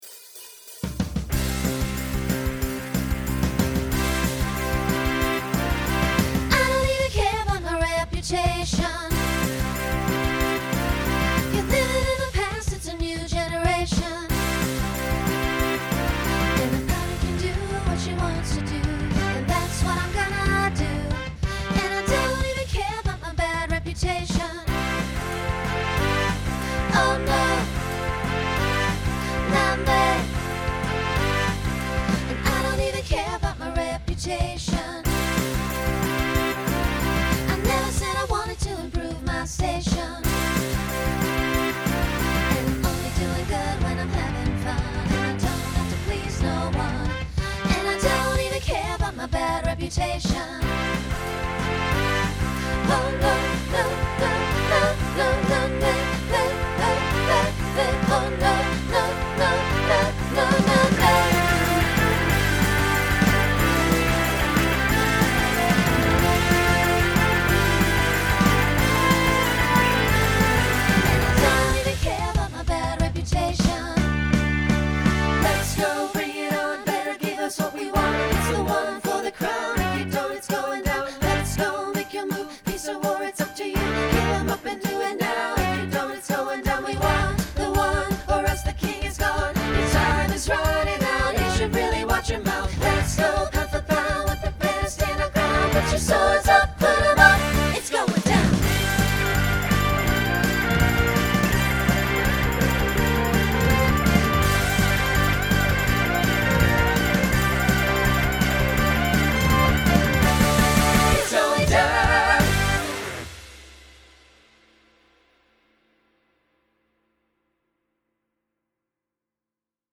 Mostly SSA with a little SATB at the end.
Genre Broadway/Film , Rock
Voicing SSA